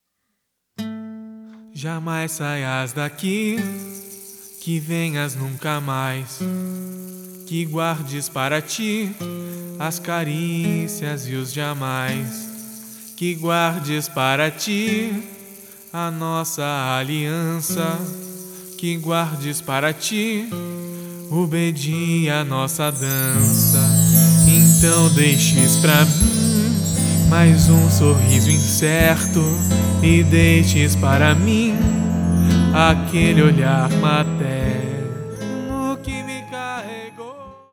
Backing Vocals, Cello, Flauta e Violão
Cajon e ganzá